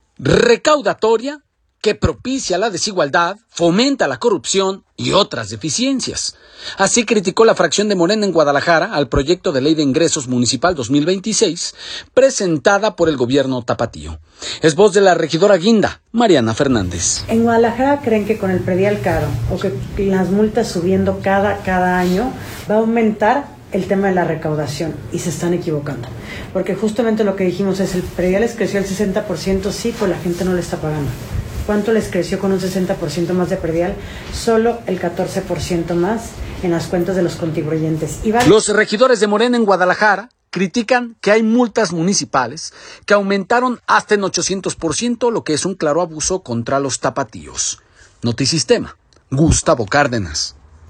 Recaudatoria, que propicia la desigualdad, fomenta la corrupción y otras deficiencias, así criticó la fracción de Morena en Guadalajara al proyecto de Ley de Ingresos Municipal 2026, presentada por el Gobierno tapatío. Es voz de la regidora guinda, Mariana Fernández.